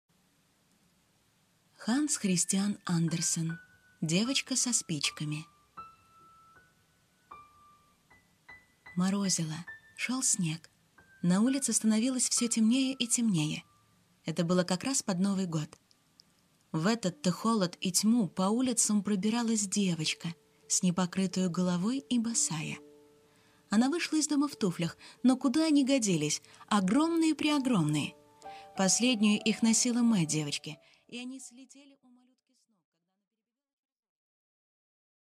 Аудиокнига Девочка со спичками | Библиотека аудиокниг
Прослушать и бесплатно скачать фрагмент аудиокниги